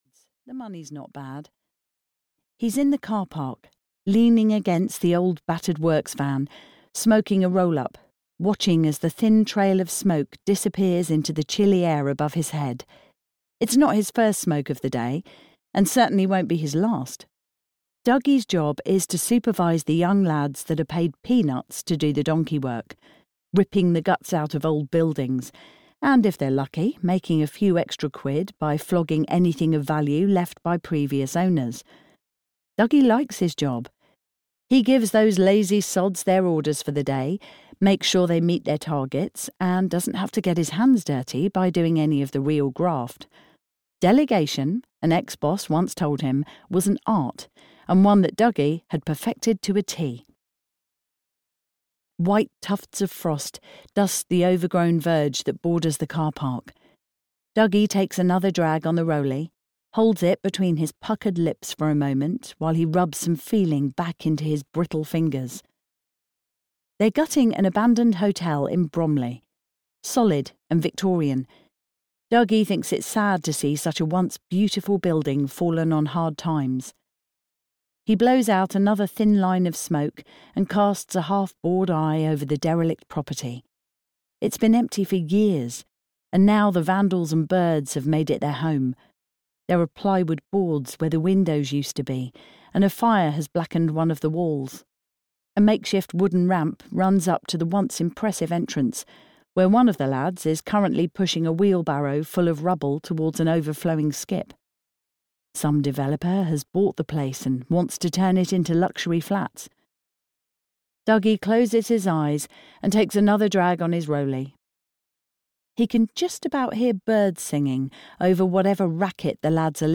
Audiobook Run for Cover, written by Graeme Hampton.
Ukázka z knihy